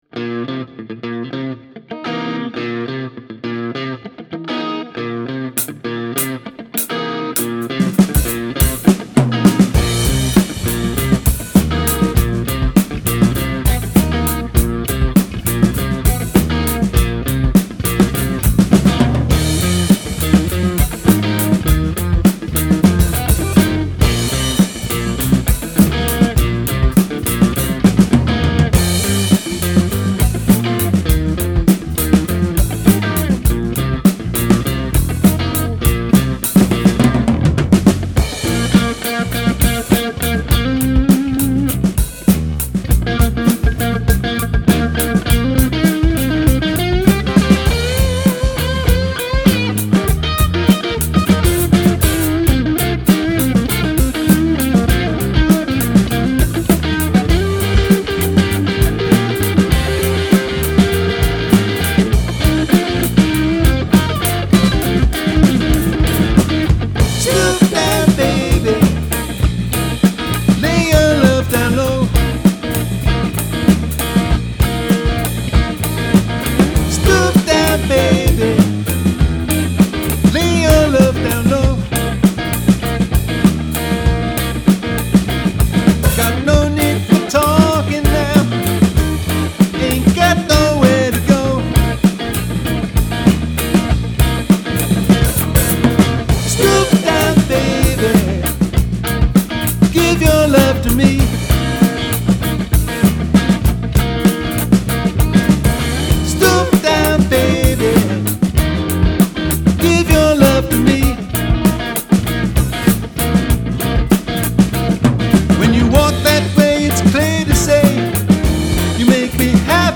Nous jouons essentiellement du rock, funk et reggae.